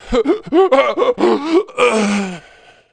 WELDER-DIE1.mp3